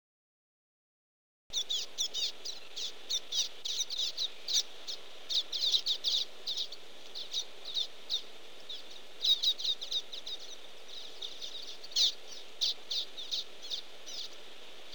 Bico-de-lacre
Estrilda astrild
Local: Praia da Manta Rota Algarve, Agosto de 2007
bico de lacre2.mp3